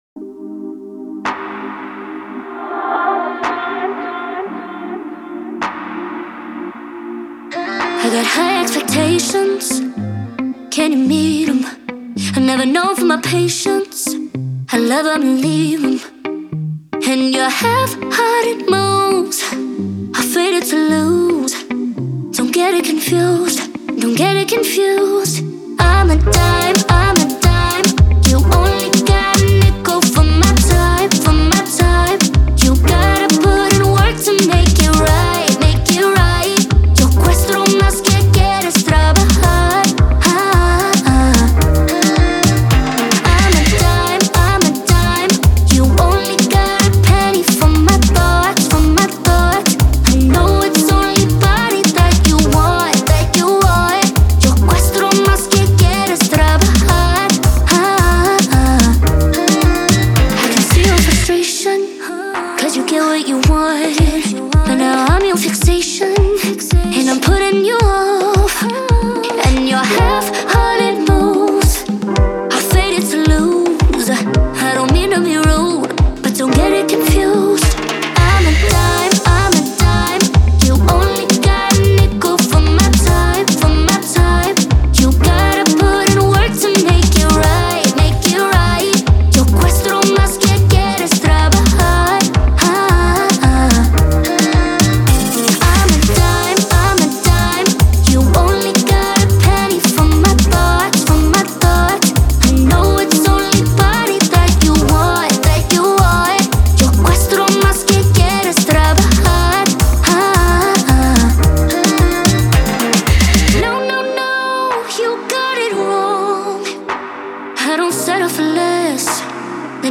выполненная в жанре поп с элементами латинской музыки.
отличается мелодичными ритмами и запоминающимся припевом
мощный вокал и эмоциональную интерпретацию